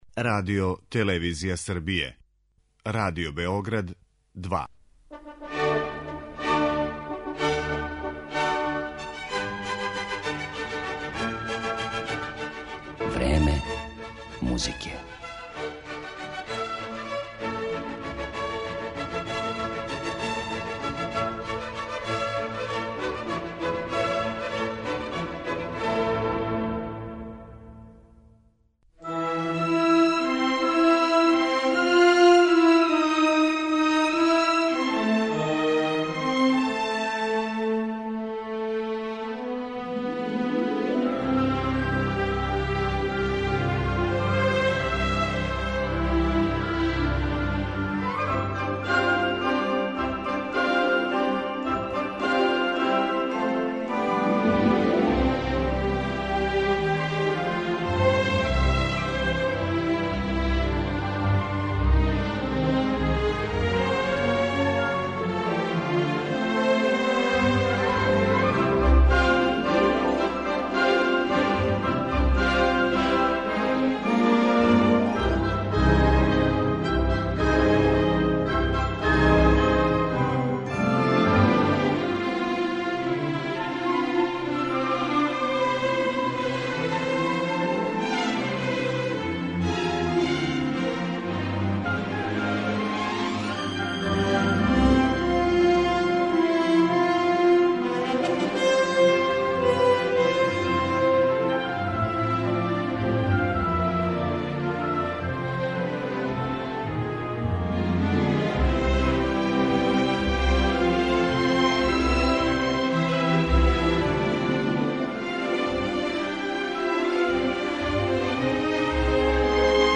Музика из холивудских филмских серијала.